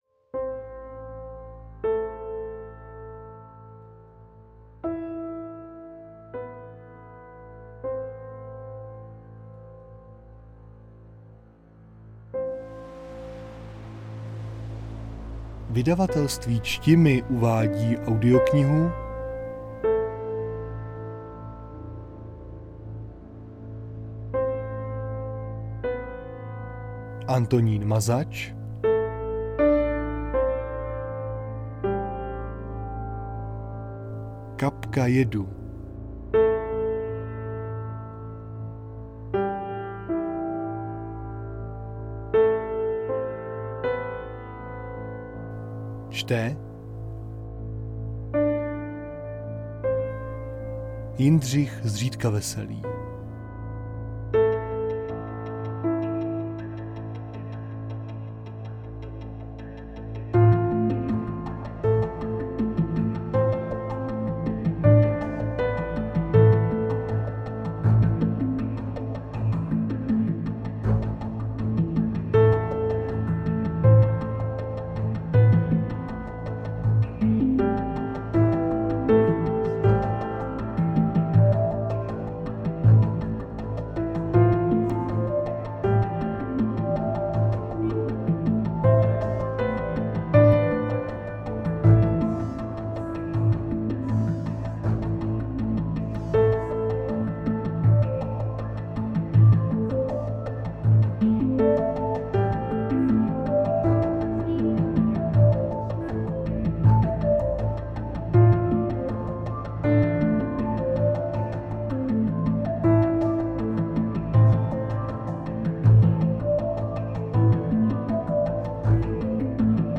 Kategorie: Detektivní, Humorné
Každý uživatel může po zakoupení audioknihy daný titul ohodnotit, a to s pomocí odkazu zaslaný v mailu.